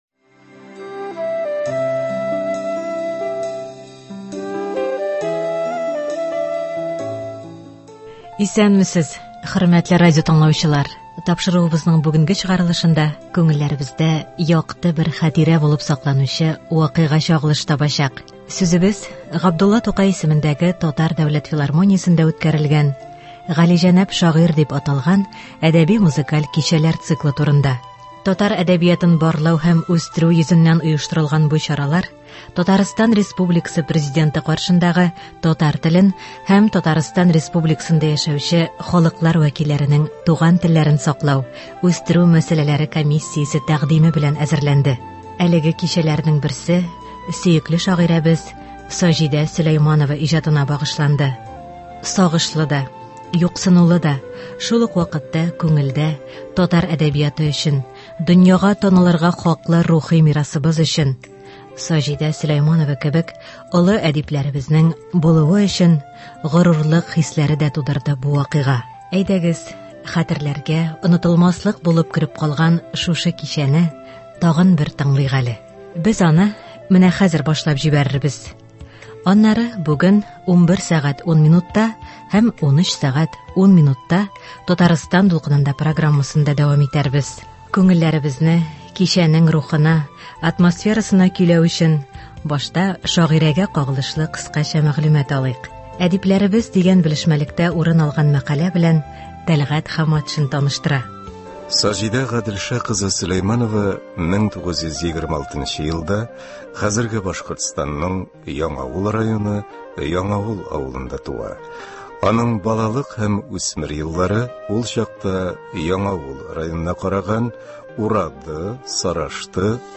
Шагыйрә Саҗидә Сөләйманова иҗатына багышланган кичәдән репортаж (08.01.23)
Сүзебез Габдулла Тукай исемендәне татар дәүләт филармониясендә үткәрелгән “Галиҗанәп шагыйрь” дип аталган әдәби-музыкаль кичәләр циклы турында.